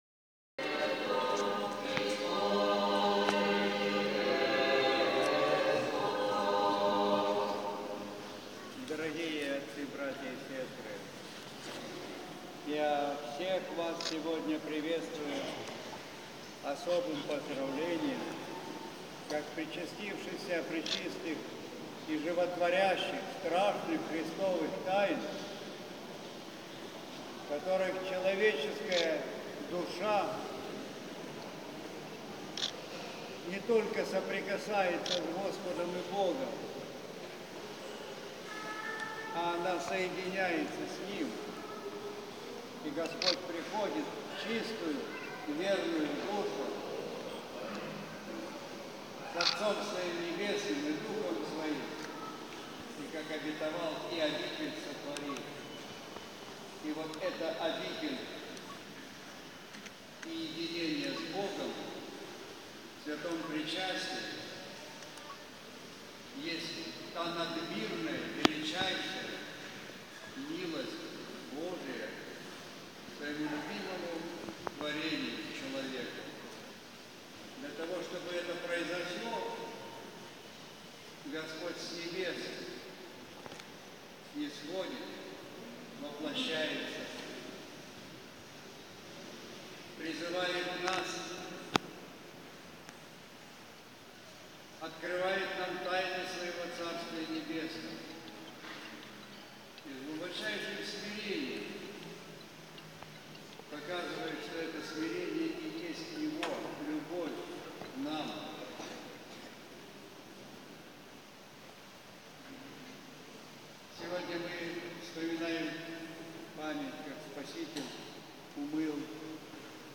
АРХИПАСТЫРСКОЕ СЛОВО МИТРОПОЛИТА САРАНСКОГО И МОРДОВСКОГО ЗИНОВИЯ В ВЕЛИКИЙ ЧЕТВЕРТОК | Храм иконы Божией Матери "Всех скорбящих Радость"